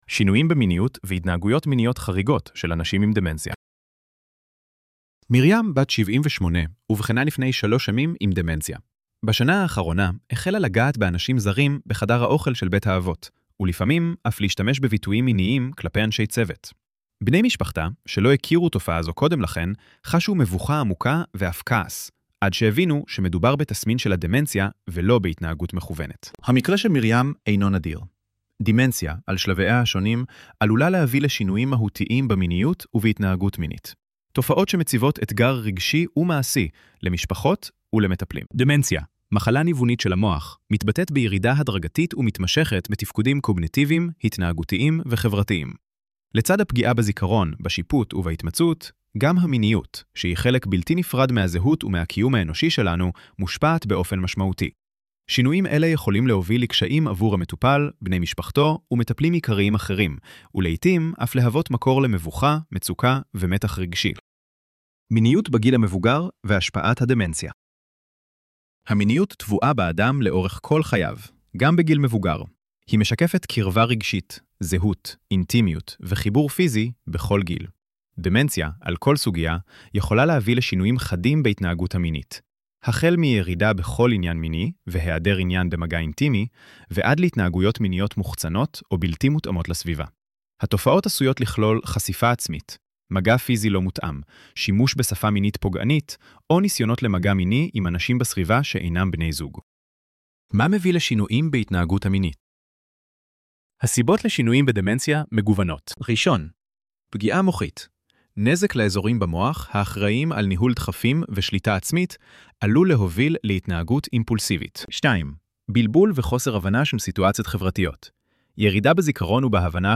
ElevenLabs_שינויים_במיניות_והתנהגויות_מיניות_חריגות_של_אנשים_עם_דמנציה.mp3